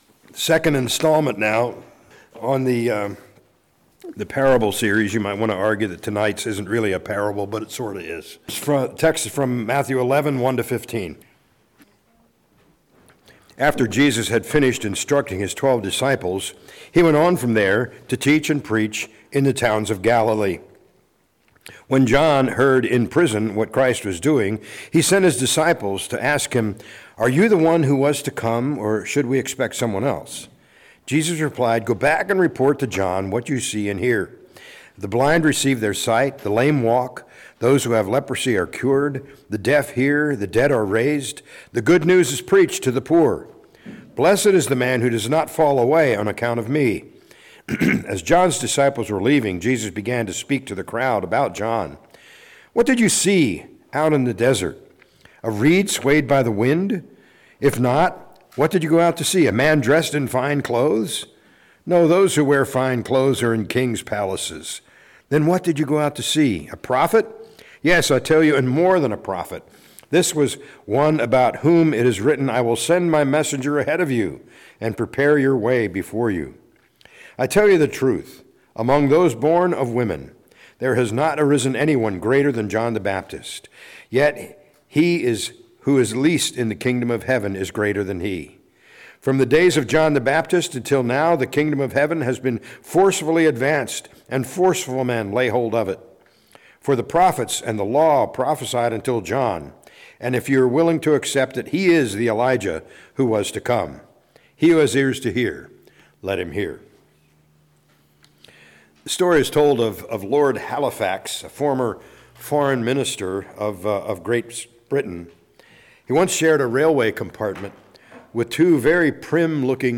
A message from the series "Parables of Jesus."